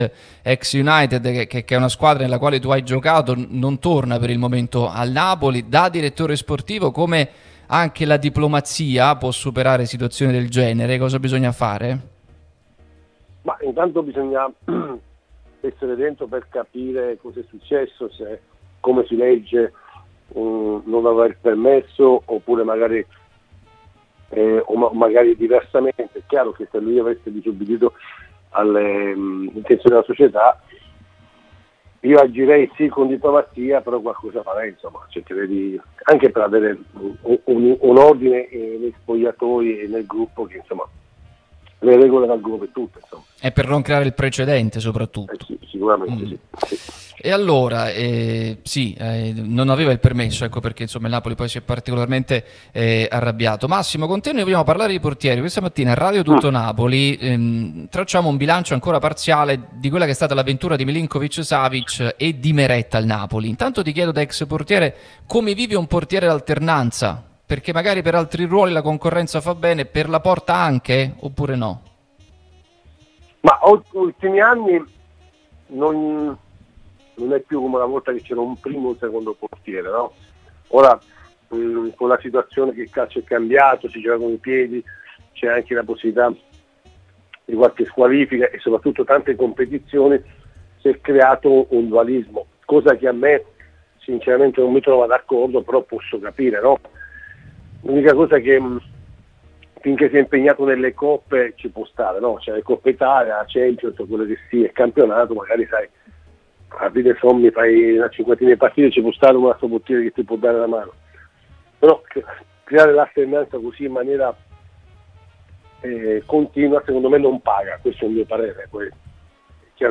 Massimo Taibi, ex portiere, è stato nostro ospite su Radio Tutto Napoli, l'unica radio tutta azzurra e sempre live, che puoi seguire sulle app gratuite (per Iphone o per Android, Android Tv ed LG), in auto col DAB o qui sul sito anche in video: "Caso Lukaku? Bisogna essere dentro per capire davvero cosa è successo.